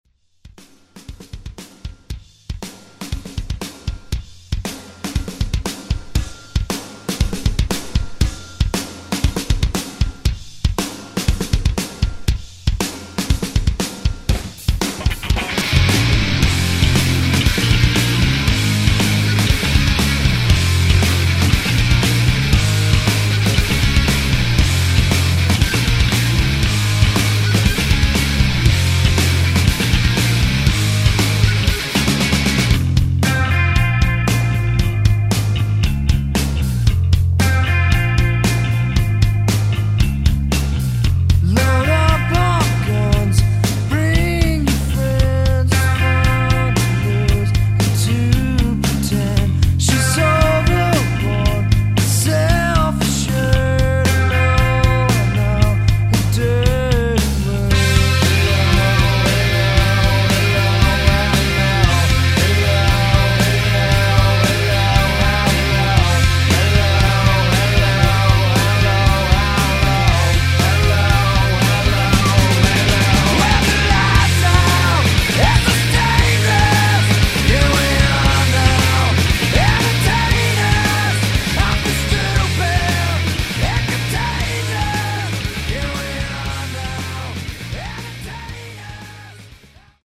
Genre: HIPHOP
Clean BPM: 100 Time